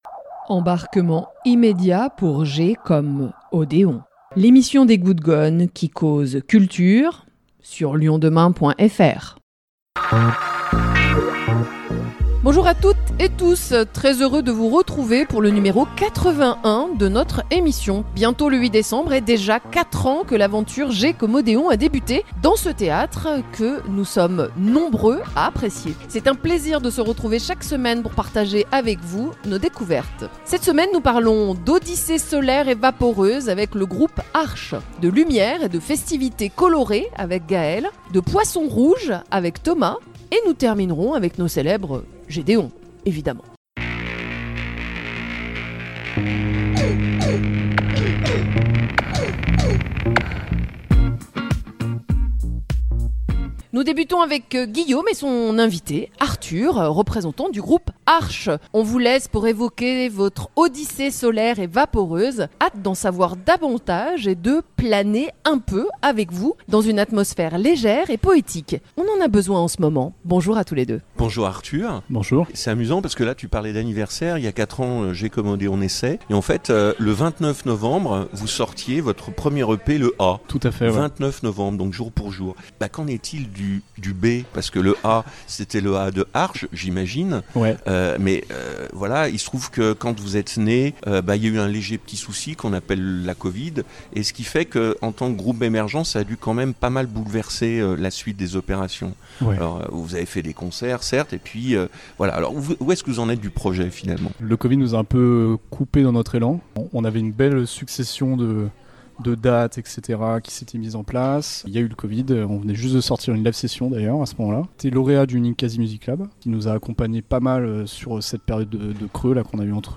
Le groupe lyonnais composé de 4 garçons aux grandes oreilles.